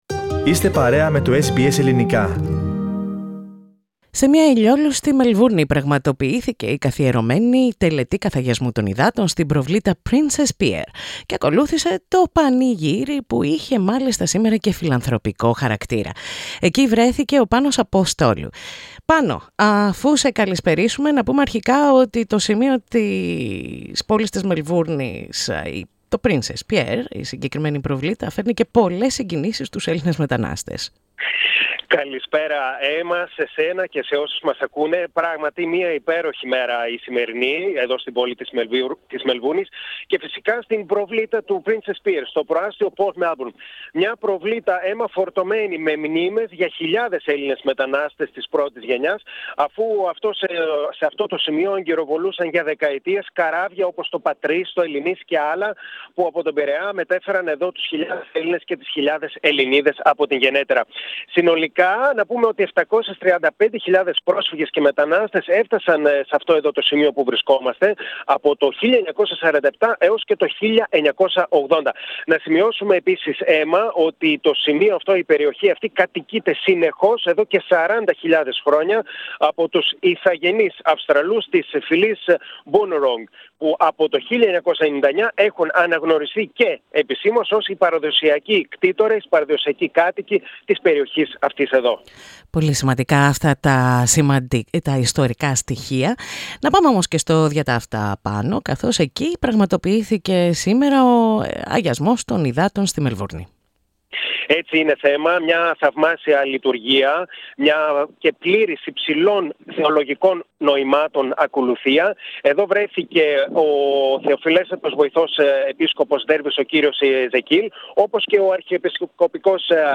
Αγιασμός των Υδάτων στη Μελβούρνη, με το νου στους πυροπαθείς της Αυστραλίας
Σε μια ηλιόλουστη Μελβούρνη πραγματοποιήθηκε η καθιερωμένη Τελετή Καθαγιασμού των Υδάτων στην προβλήτα Princes Pier, σε μια τοποθεσία που φέρνει πολλές συγκινήσεις στους Ελληνες μετανάστες.
Εκεί βρέθηκε το SBS Greek.